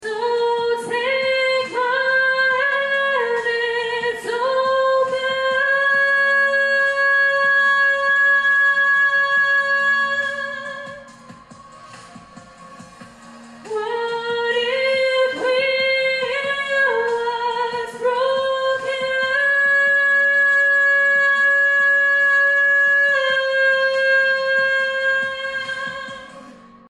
Decided to attempt the bridge of the song!